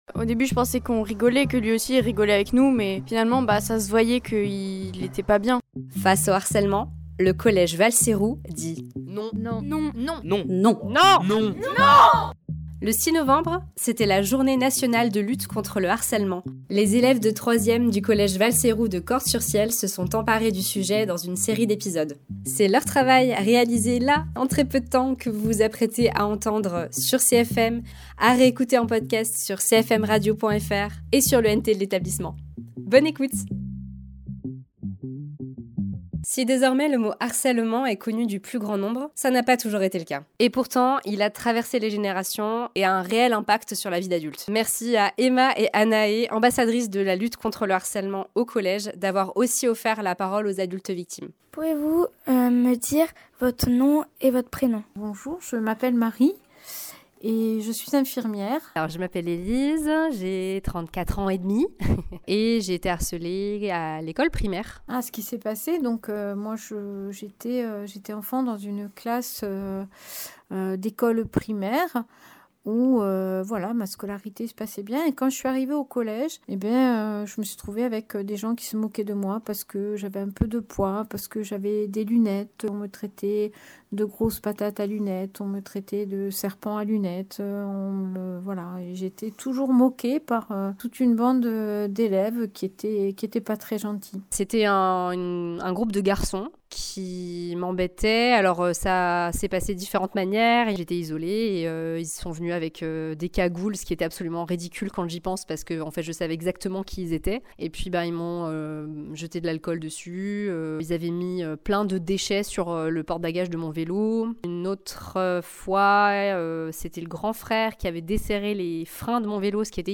Des années après les faits, les blessures du harcèlement scolaire peuvent encore marquer profondément une vie. Dans cet épisode, deux témoins racontent leur parcours et les répercussions durables de ces violences sur les adultes qu’elles sont devenues, entre mémoire, reconstruction et parole libérée.